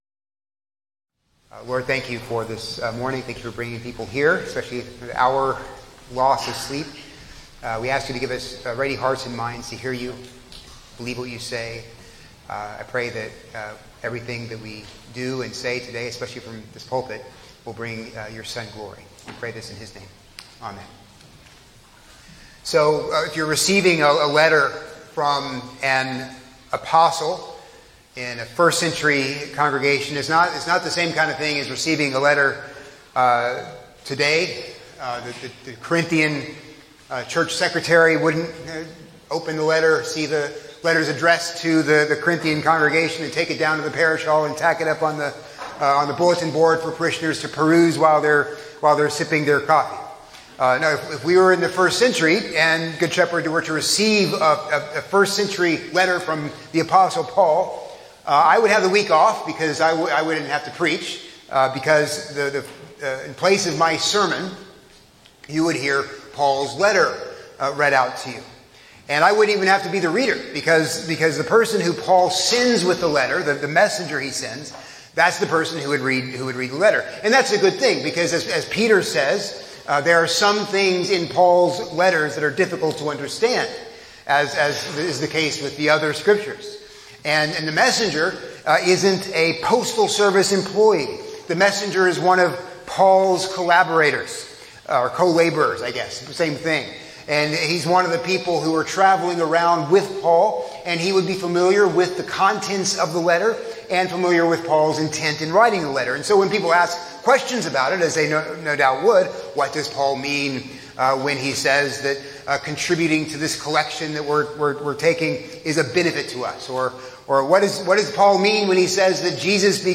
A sermon on II Corinthians 8:16-24by